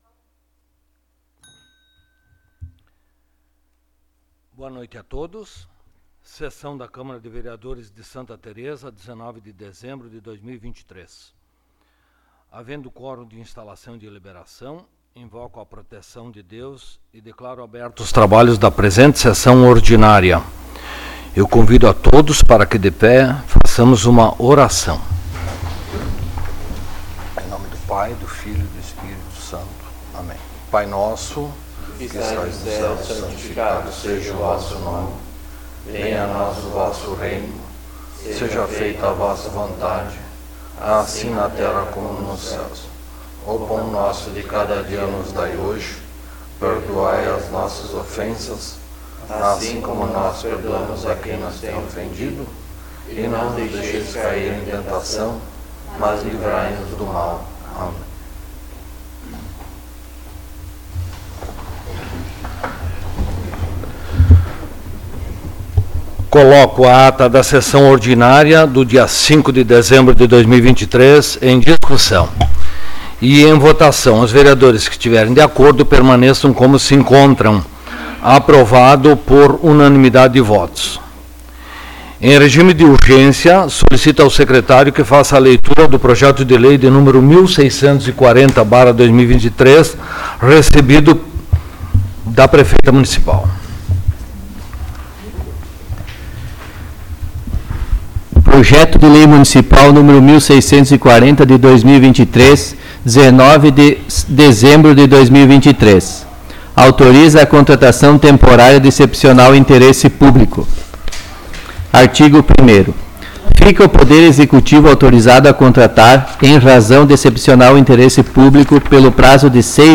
22ª Sessão Ordinária de 2023